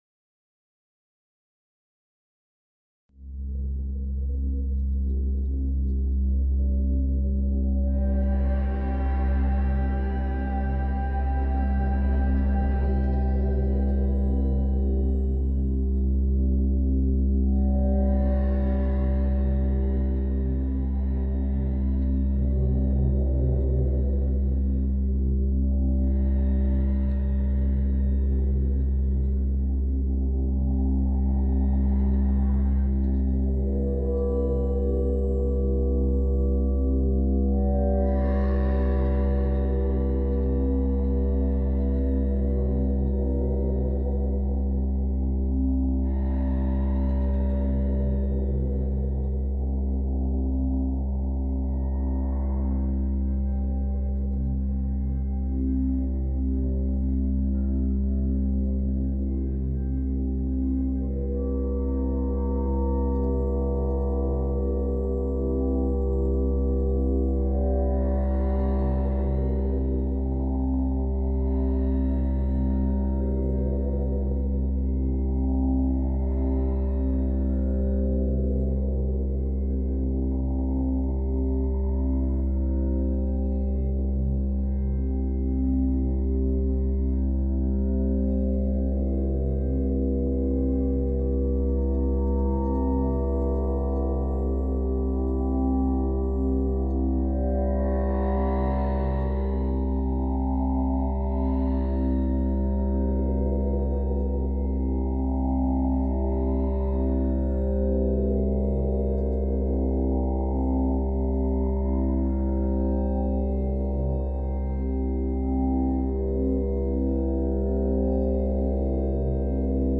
音の波紋が、時を包む。 129Hzの響きとシンギングボールの倍音が広がる、8分の瞑想時間。